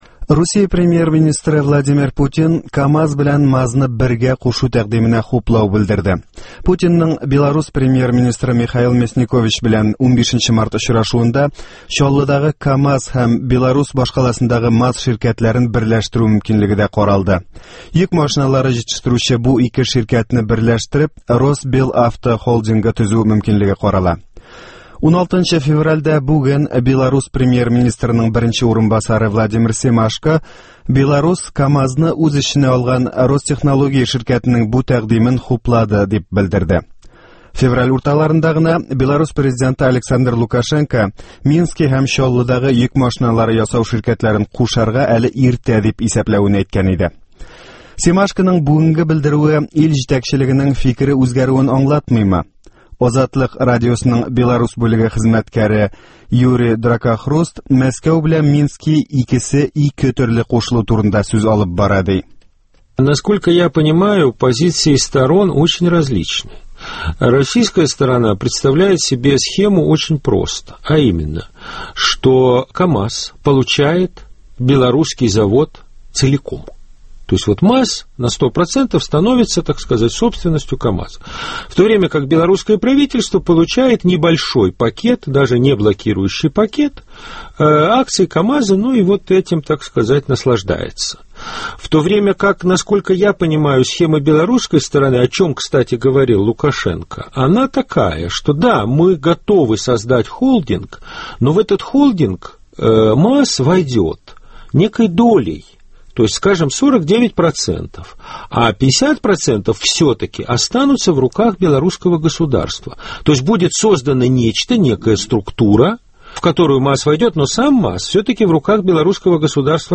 МАЗ белән КамАЗның кушылу ихтималы турында әңгәмә